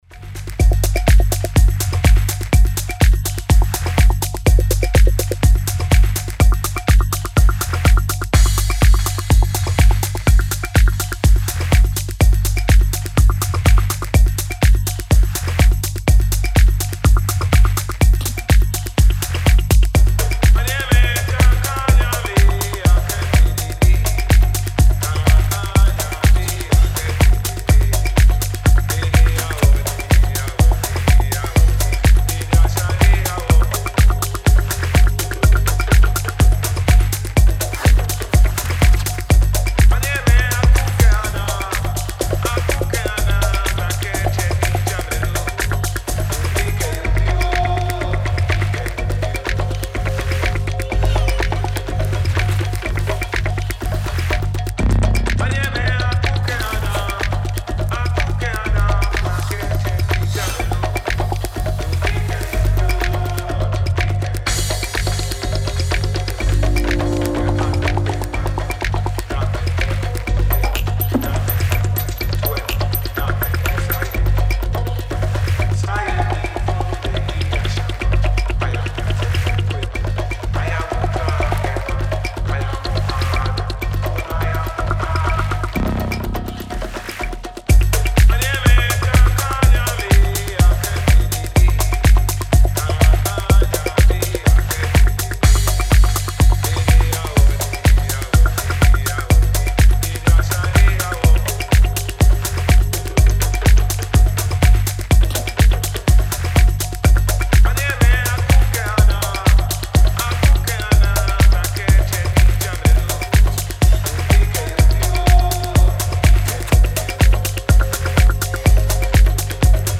Style: Techno / Minimal